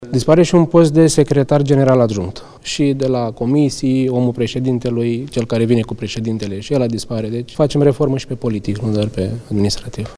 Și posturi importante vor fi disponibilizate, a anunțat președintele Camerei Deputaților, Ciprian Șerban.
29ian-13-Ciprian-Serban-posturi-importante.mp3